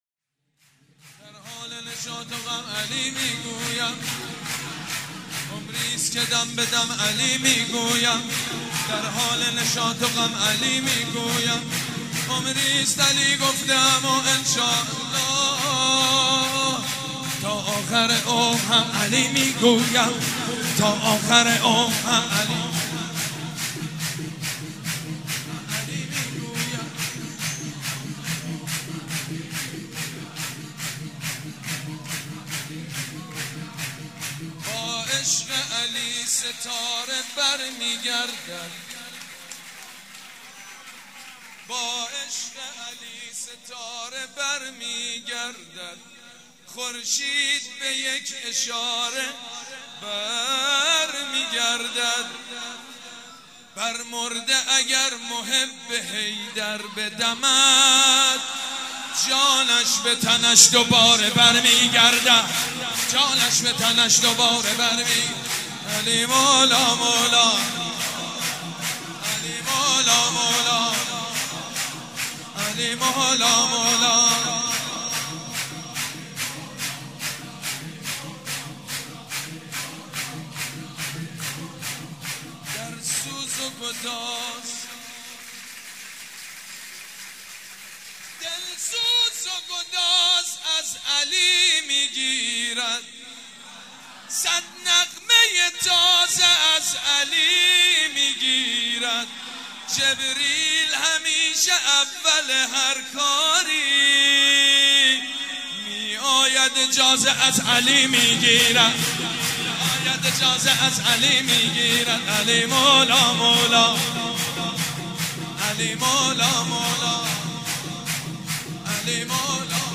شب ولادت پیامبر اکرم و حضرت امام جعفر صادق(ع)
شور
حاج سید مجید بنی فاطمه